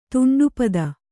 ♪ tuṇḍu pada